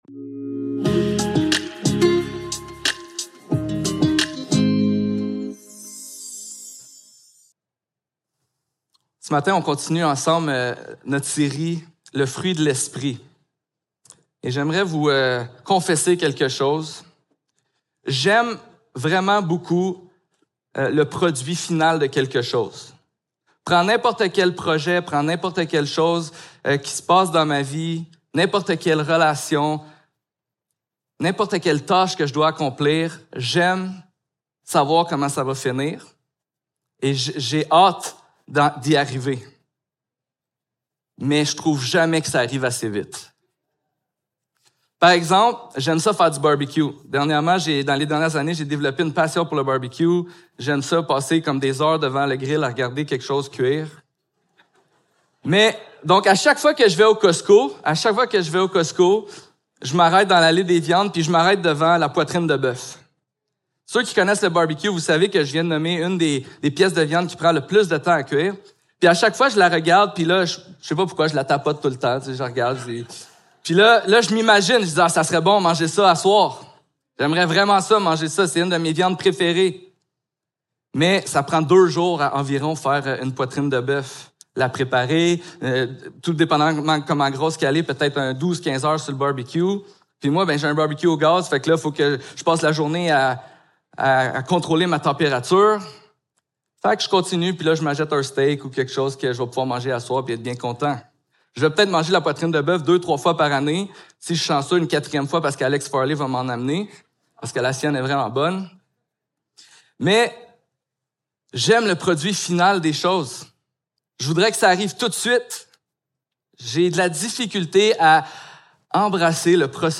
Ésaïe 57.14-21 Service Type: Célébration dimanche matin Description